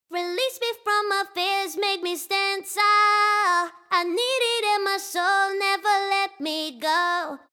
男声ボーカルを女性ボーカルに変えて仮歌を作成
▼ReSingで変換した女性ボーカル